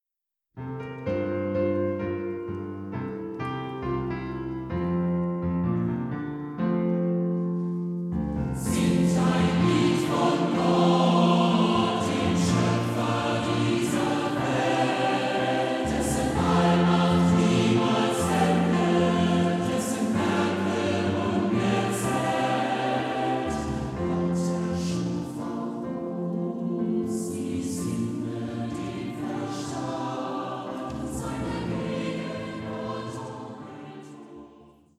• kurzweilige Zusammenstellung verschiedener Live-Aufnahmen
Chor, Klavier